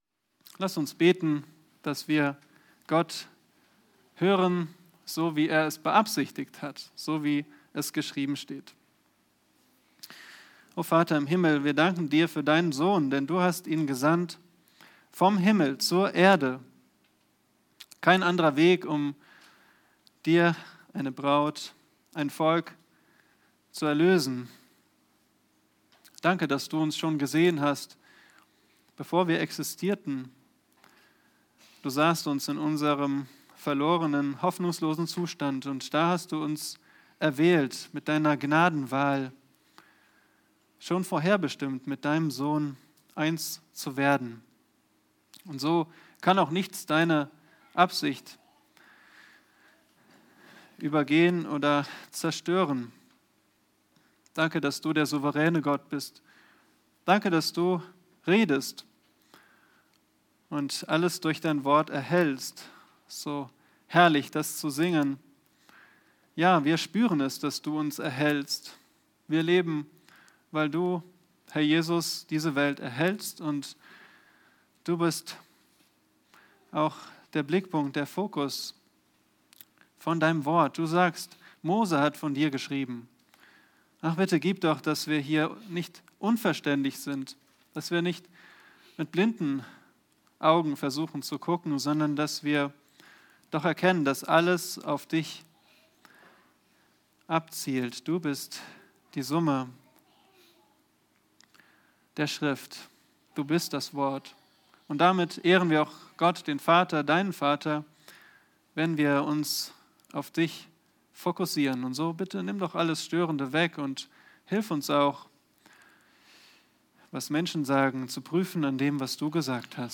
Predigt-Archiv - Bibelgemeinde Barnim